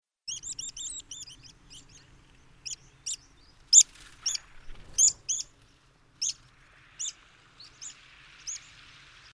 Vuorikirvinen / Water Pipit (Anthus spinoletta)